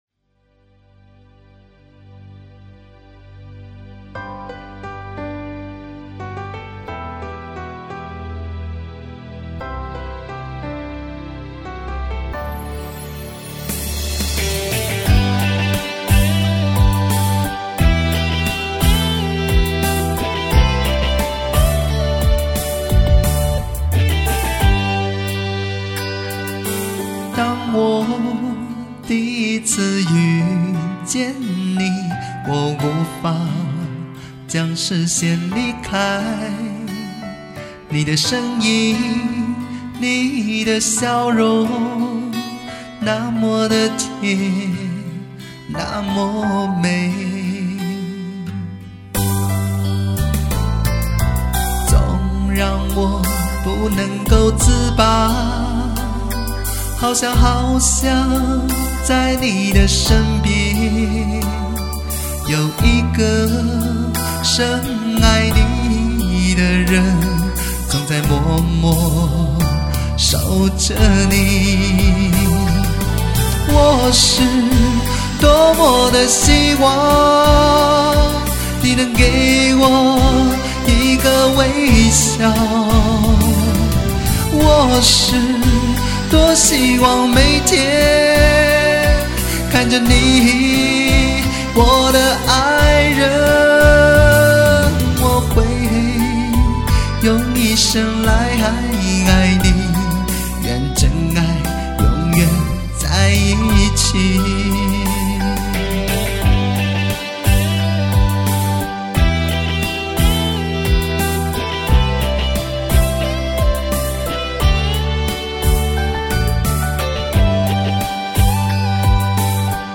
具有磁性的嗓音